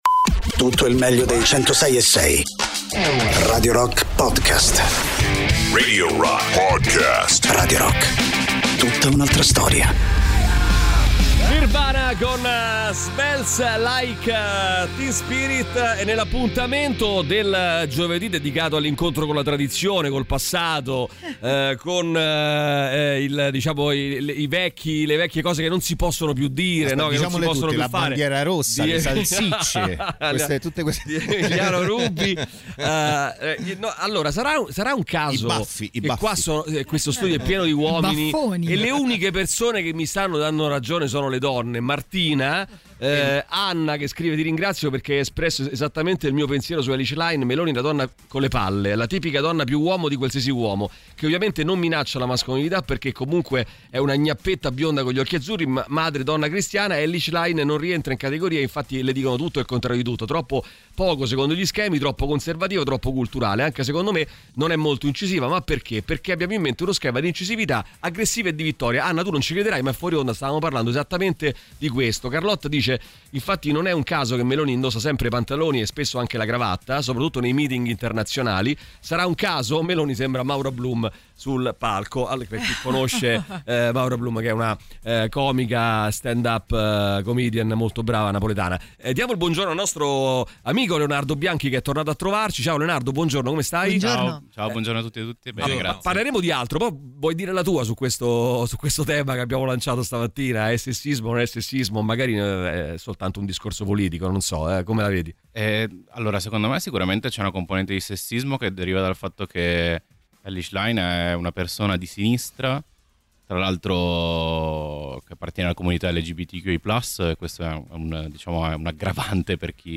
Interviste
ospite in studio